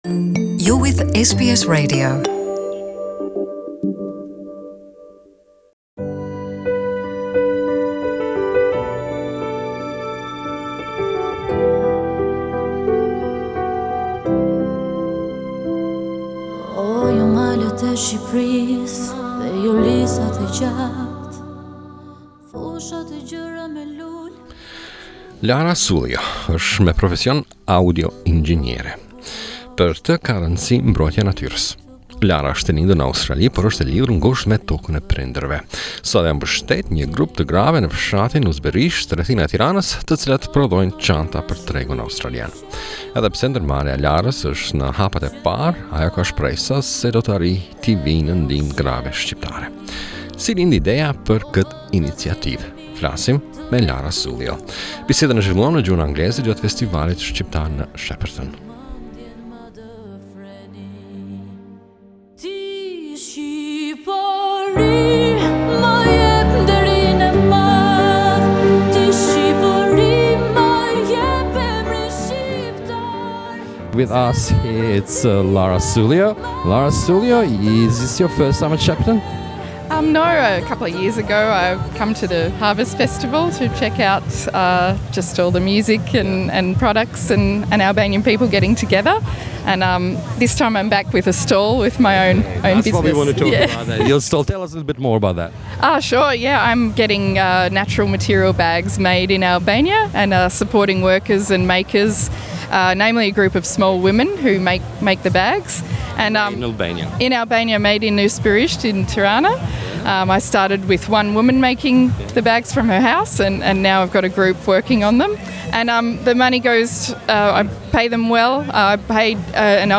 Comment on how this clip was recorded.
The conversation was conducted in English language during the Albanian festival in Shepparton.